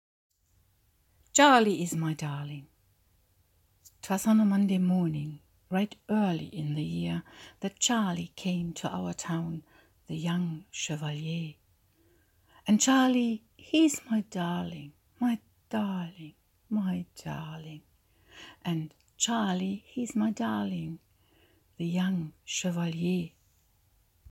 Volkslied auf Bonnie Prince Charlie, von Fontane übersetzt und auf die Originalmelodie zu singen: „An einem Montagmorgen war’s Kaum schlug die Glocke vier, Da zog er ein in unsre Stadt, Der junge Kavalier.